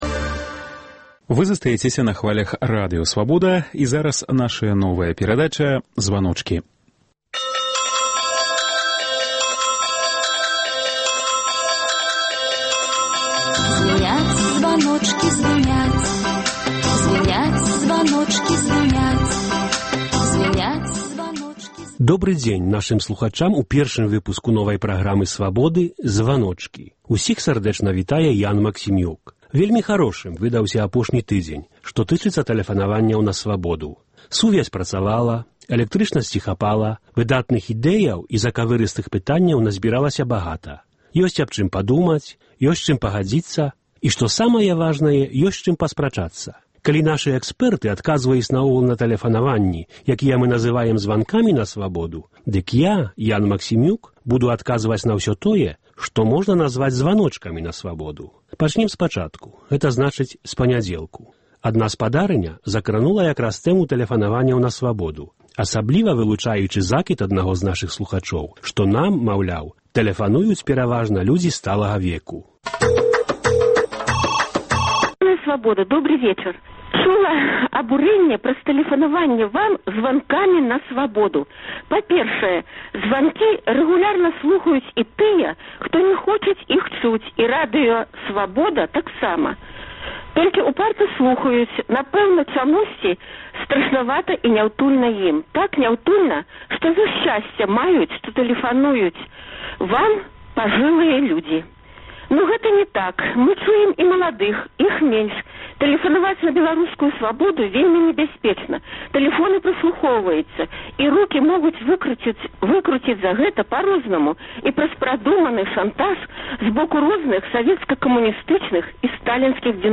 Вось праблемы, якія хвалююць нашых слухачоў у званках на "Свабоду".